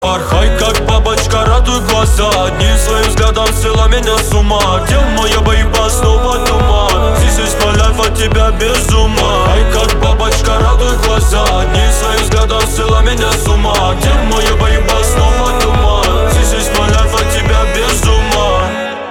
• Качество: 320, Stereo
ритмичные
мужской вокал